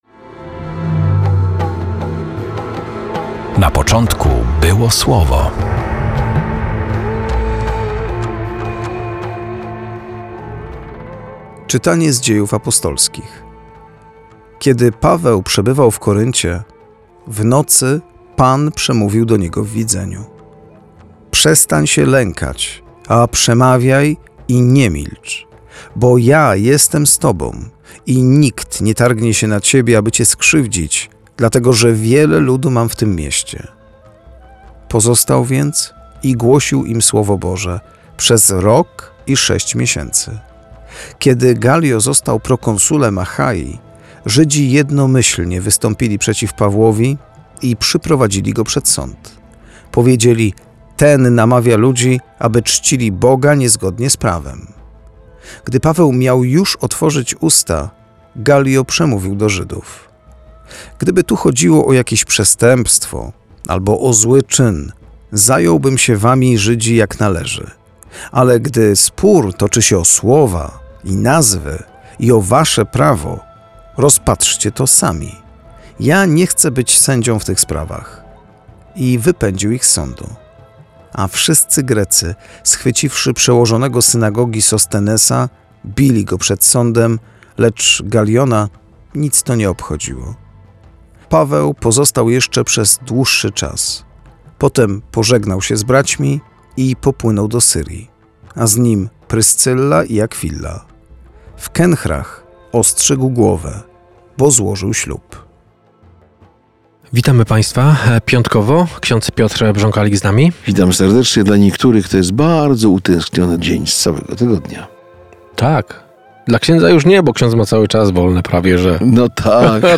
Perły ukryte w liturgii słowa odkrywają księża